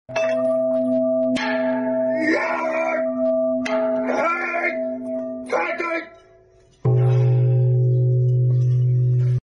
Inherit his father's bell ringing sound effects free download
You Just Search Sound Effects And Download. tiktok funny sound hahaha Download Sound Effect Home